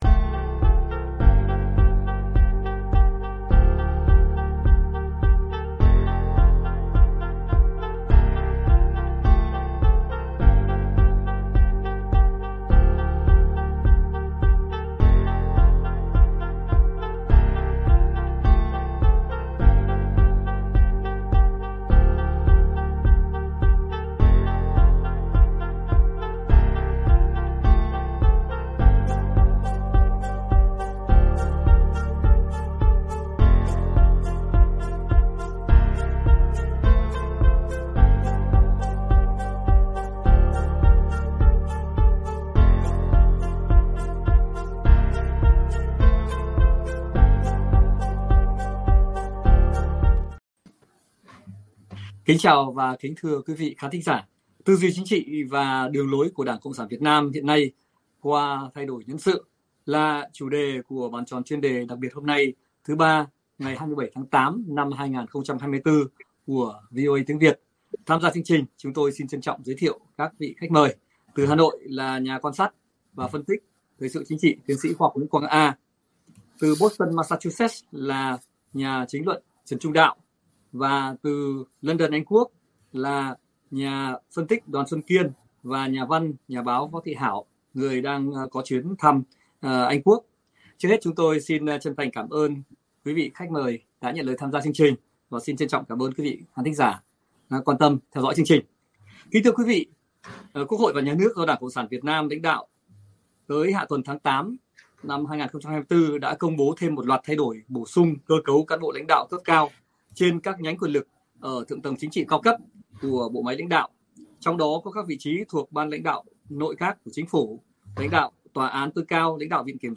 Giới quan sát thời sự, hoạt động chính trị từ Việt Nam bình luận các điều chỉnh, bổ sung, và phương án thay đổi nhân sự được chính quyền của TBT. CTN Tô Lâm mới công bố và thử bàn liệu có chỉ dấu nào về chính sách, đường lối đáng hướng tới từ đó?